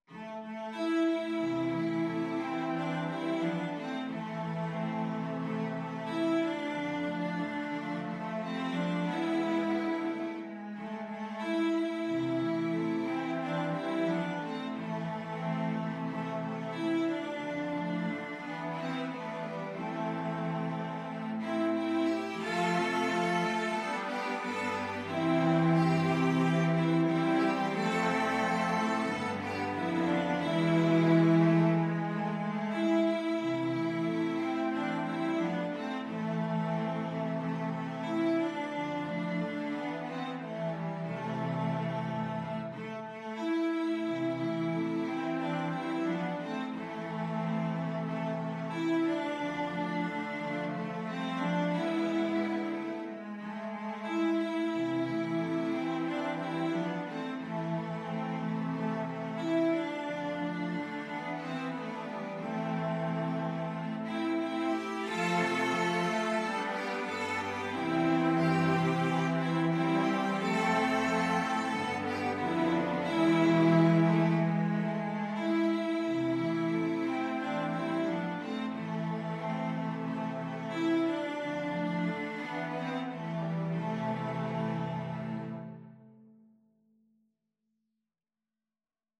Free Sheet music for Cello Quartet
Cello 1Cello 2Cello 3Cello 4
4/4 (View more 4/4 Music)
Andante = c. 90
A minor (Sounding Pitch) (View more A minor Music for Cello Quartet )
Traditional (View more Traditional Cello Quartet Music)